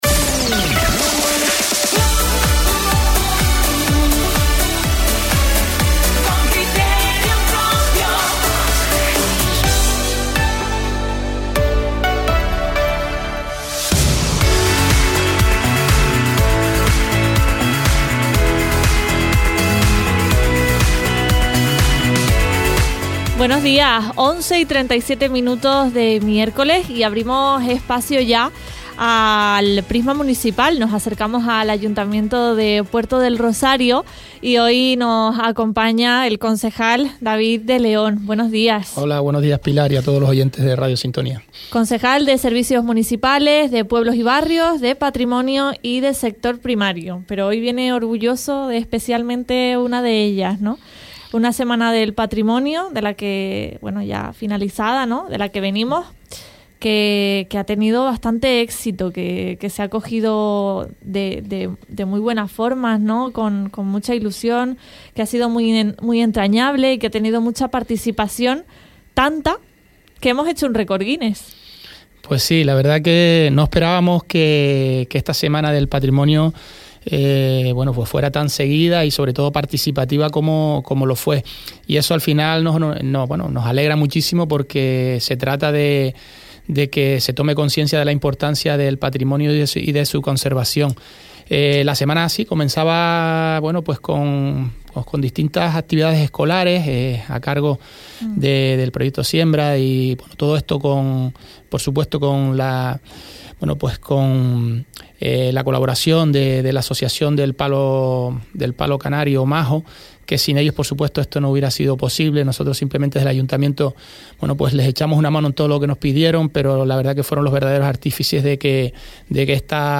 David de León, concejal de Patrimonio en Puerto del Rosario se acerca esta mañana a los micrófonos de la radio.